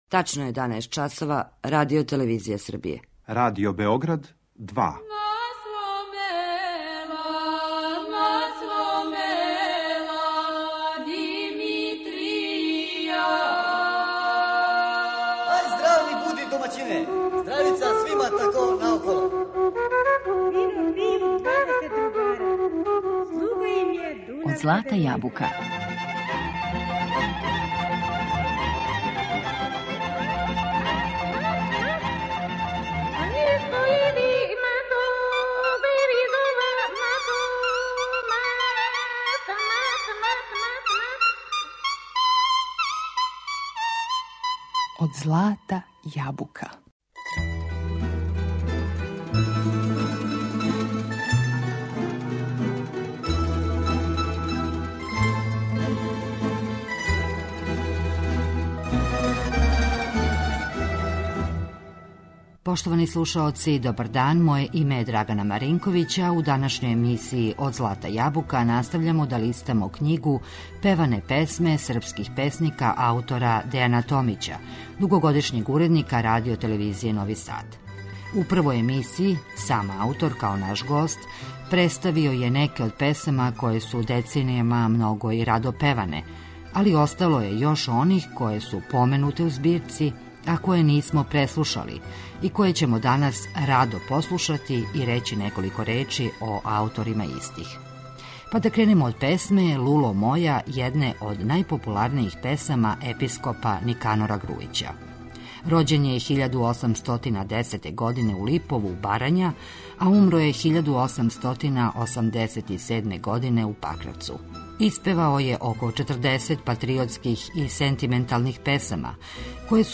Послушаћемо неке народне песме чији су аутори стихова Никанор Грујић, др Јован Суботић, Мита Орешковић, Ђорђе Малетић, Петар Прерадовић, прота Васа Живковић, Јован Илић и Бранко Радичевић.